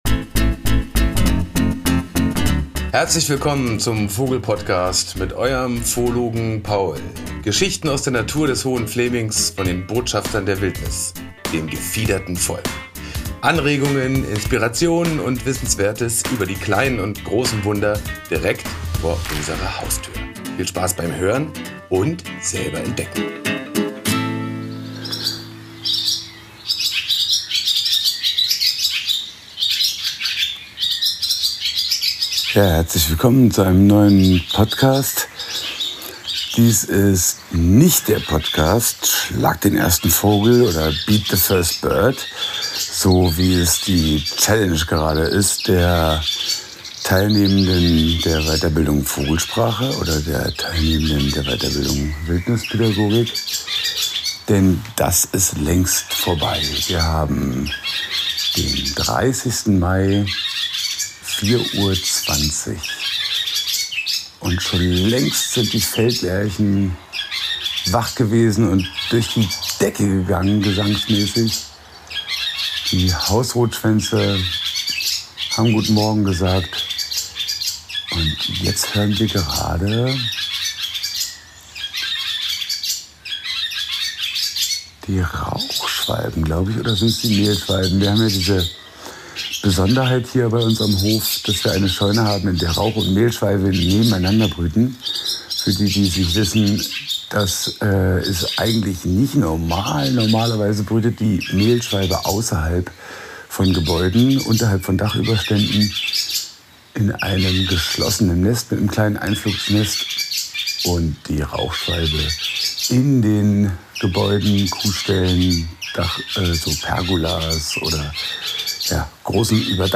(Empfehlung: Klangbildreise mit Kopfhörern genießen!)
Aus jeder Hecke, jedem Baum und jedem Strauch sind Bettelrufe der flüggen Jungvögel zu hören, sie schreien nach Futter und die Altvögel müssen liefern.
Während der Großteil der Menschenwelt noch schläft, tobt das Morgenkonzert der Singvögel, Rehe, Hasen und Wildschweine genießen ihr Frühstück. Auf der Suche nach Wiedehopf und einem Rätselvogel offenbart sich plötzlich eine unerwartete Überraschung.